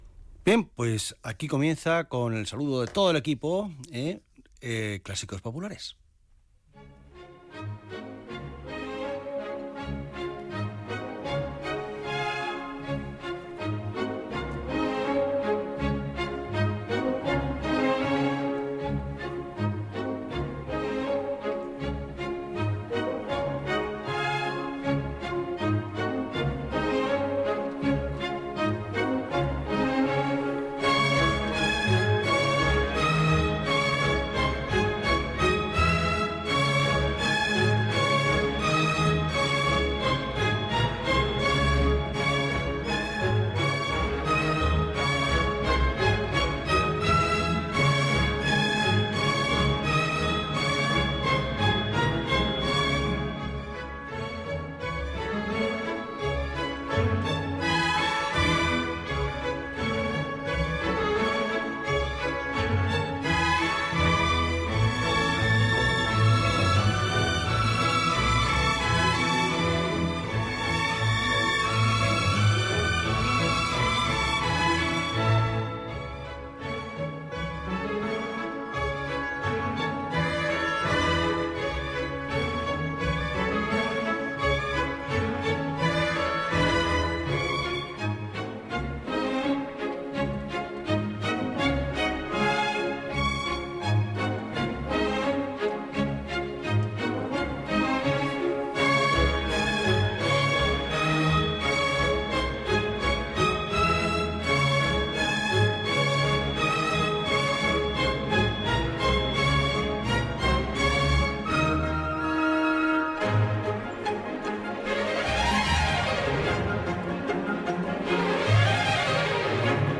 Salutació, tema musical, comentari sobre el que s'ha escoltat i presentació del següent tema musical
Musical